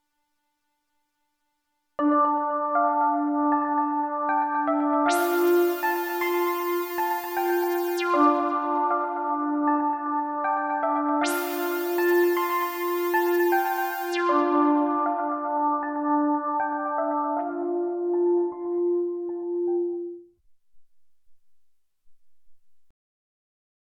78 BPM
Pensive — focus, concentrate
D dorian sparse arpeggio over low modal drone